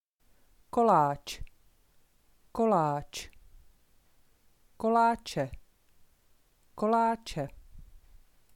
kolache-pronunciation.mp3